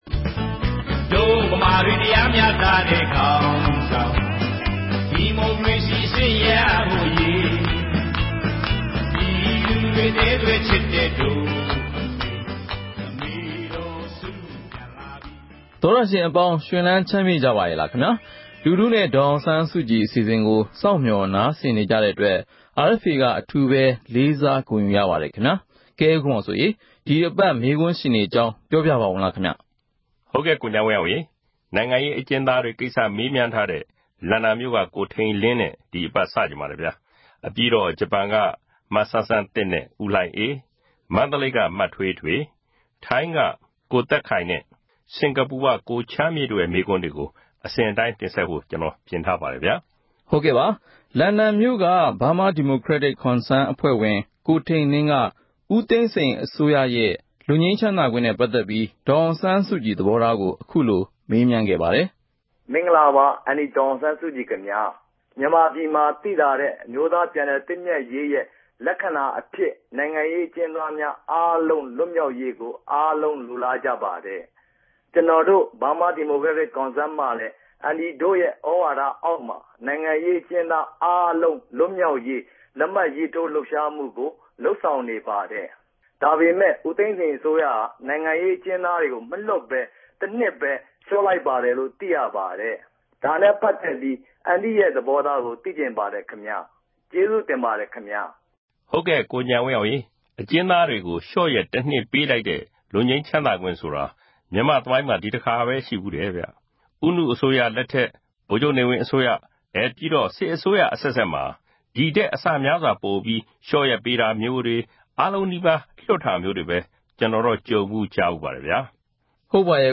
လူထုနဲ့ ဒေါ်အောင်ဆန်းစုကြည် အစီအစဉ်ကို RFA က အပတ်စဉ် သောကြာနေ့ ညတိုင်းနဲ့ ဗုဒ္ဓဟူးနေ့ မနက်တိုင်း တင်ဆက်နေပါတယ်။ ဒီ အစီအစဉ်ကနေ ပြည်သူတွေ သိချင်တဲ့ မေးခွန်းတွေကို ဒေါ်အောင်ဆန်းစုကြည် ကိုယ်တိုင် ဖြေကြားပေးမှာ ဖြစ်ပါတယ်။